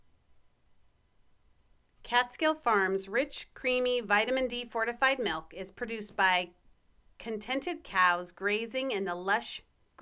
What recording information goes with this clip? I turned the volume up on my external mic, new sample. Volume is perfect, but you sound like you’re announcing in a small room.